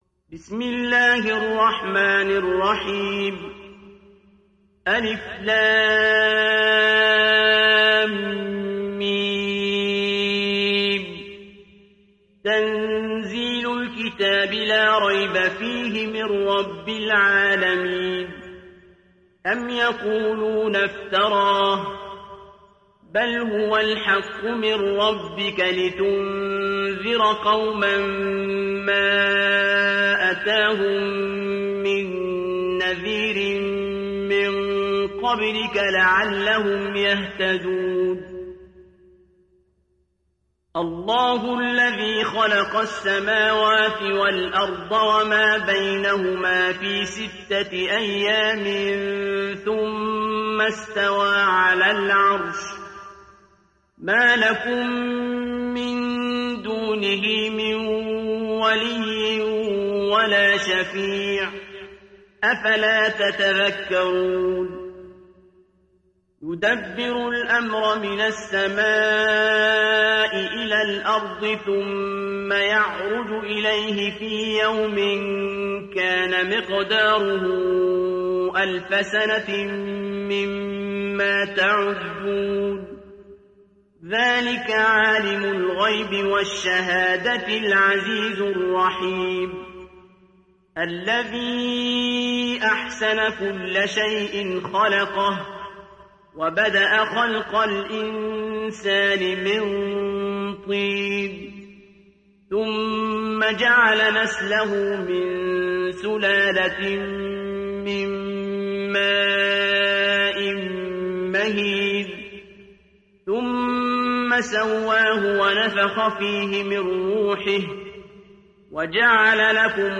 Surat As Sajdah mp3 Download Abdul Basit Abd Alsamad (Riwayat Hafs)
Surat As Sajdah Download mp3 Abdul Basit Abd Alsamad Riwayat Hafs dari Asim, Download Quran dan mendengarkan mp3 tautan langsung penuh